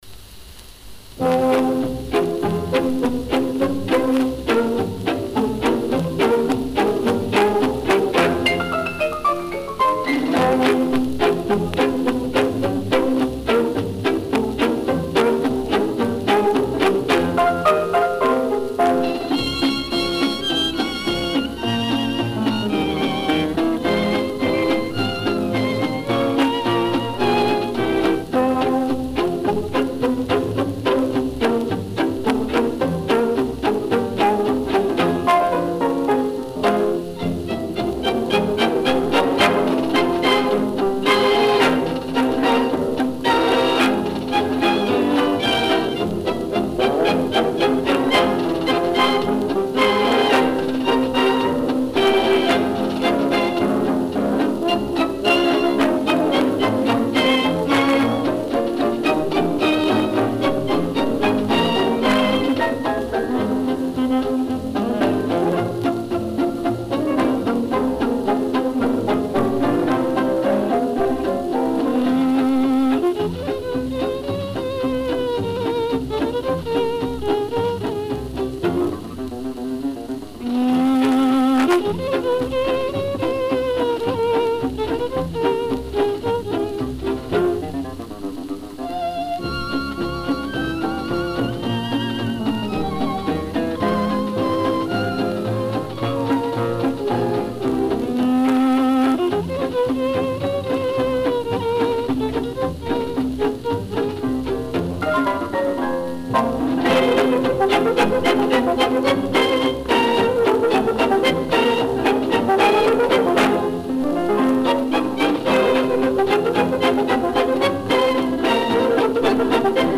Каталожная категория: Танцевальный оркестр |
Жанр: Фокстрот
Место записи: Берлин |